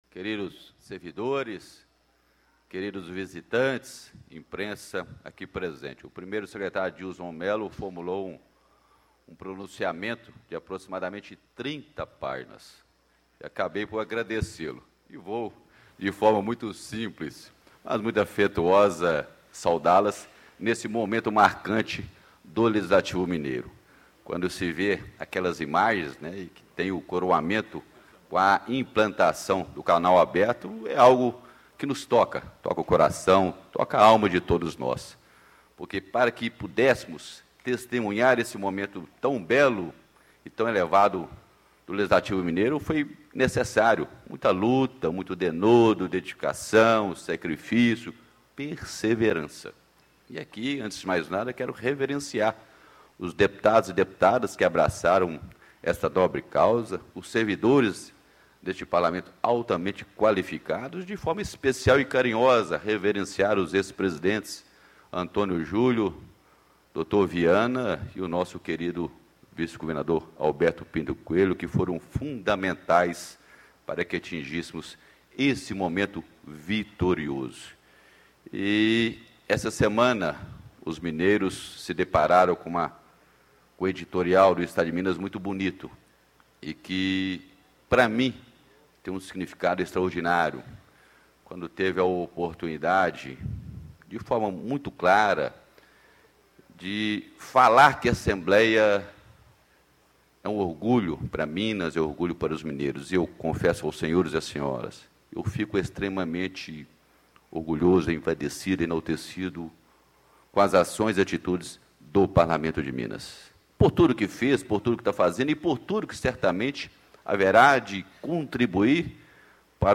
Deputado Dinis Pinheiro, PSDB - Presidente da Assembleia Legislativa do Estado de Minas Gerais
Encerramento da 1ª Sessão Legislativa da 17ª Legislatura
Discursos e Palestras